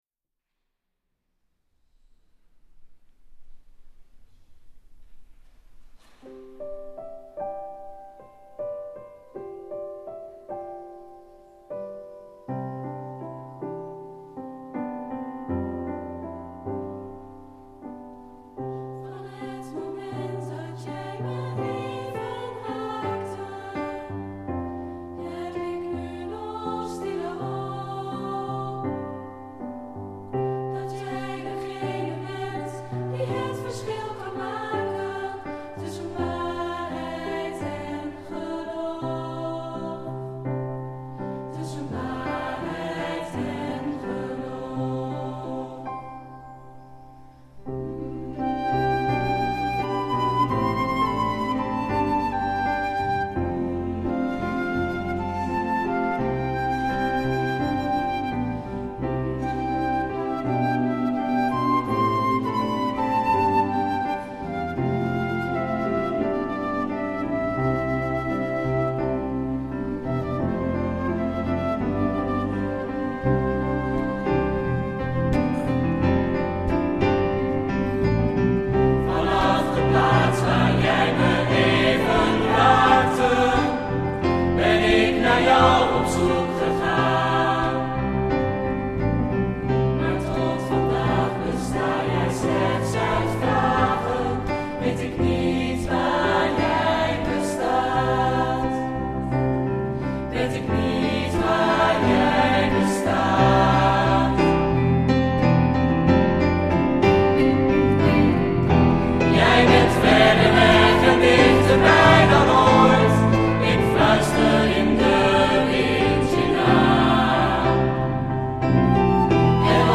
We maken keuzes uit bestaand katholiek jongerenkoor-repertoire,  schrijven zelf teksten en arrangementen op bestaande popliedjes en lenen liedjes van andere koren.